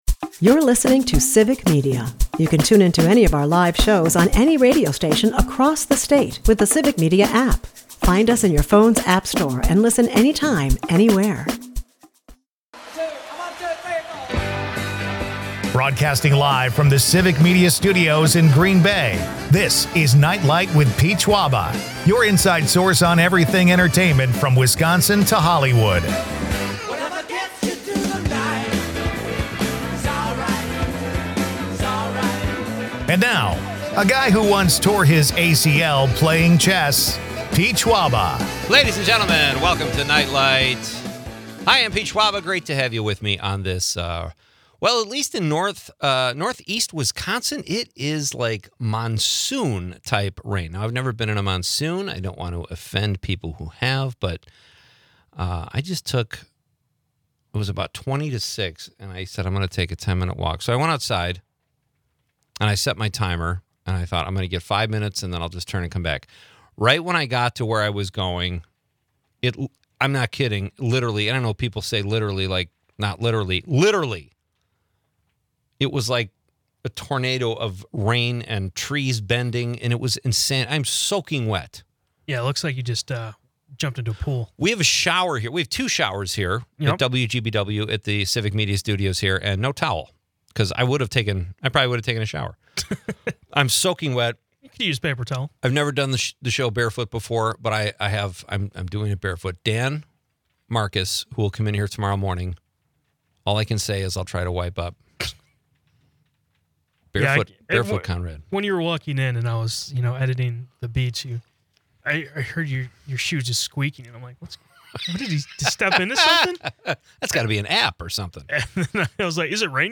Listeners weigh in on their least favorite sports, from golf and NASCAR to underwater basket weaving.